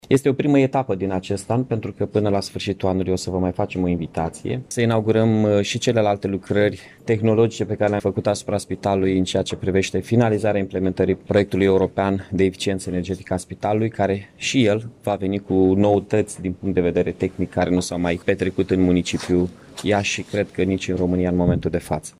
Primarul Iașiului, Mihai Chirica, a declarat că până la sfârșitul acestui an va fi definitivat proiectul de eficientizare energetică a clădirii.